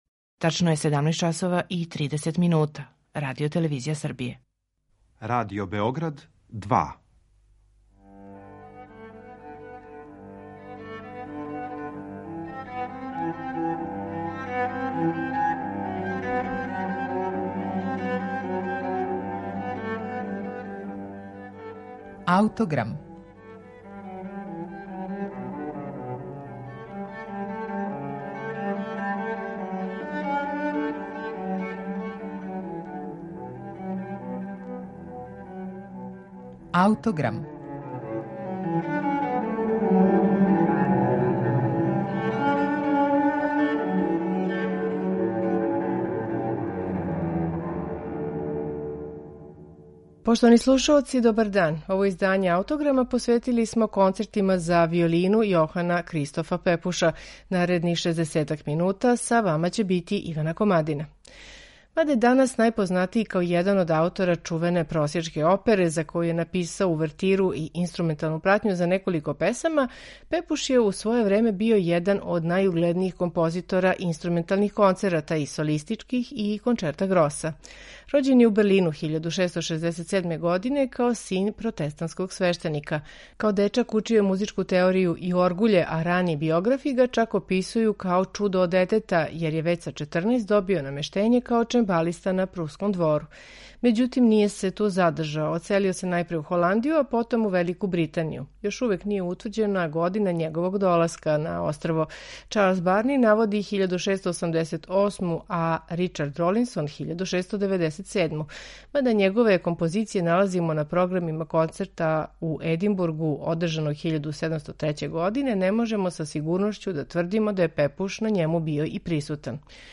Јохан Пепуш: Концерт за виолину
Данашњи Аутограм посветили смо Пепушевим концертима за виолину. Први од њих, Concerto grosso за виолину и гудаче у Б-дуру је, уједно, први сачувани инструментални концерт настао у Великој Британији. Пепушове концерте слушаћете у интерпретацији оркестра Harmonious Society of Tickle-Fiddle Gentlemen .